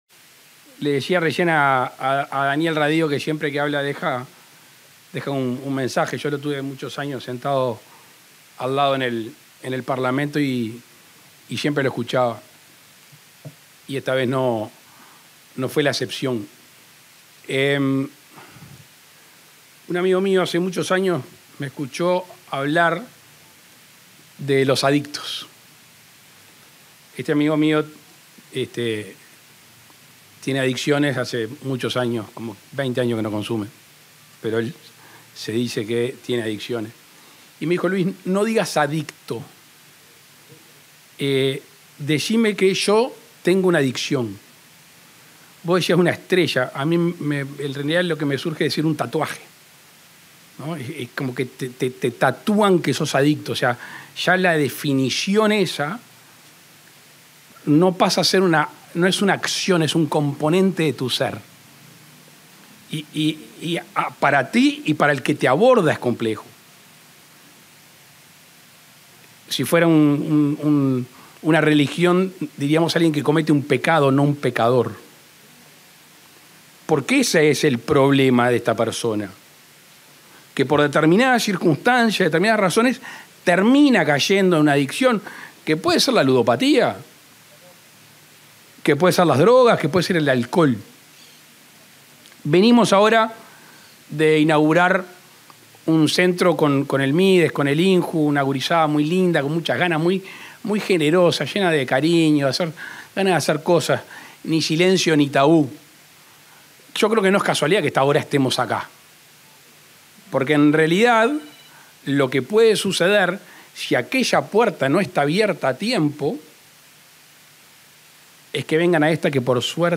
Palabras del presidente de la República, Luis Lacalle Pou
Palabras del presidente de la República, Luis Lacalle Pou 31/07/2024 Compartir Facebook X Copiar enlace WhatsApp LinkedIn En el marco de la inauguración de un dispositivo Ciudadela de la Secretaría Nacional de Drogas (SND) para establecer un sistema de atención para personas con problemas por adicciones, este 31 de julio, se expresó el presidente de la República, Luis Lacalle Pou.